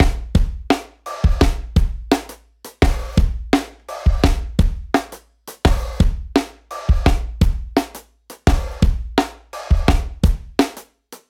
60s Lo-Fi（DB-30 OFF / ON）
60s-Lo-Fi Snare-DRY[659].mp3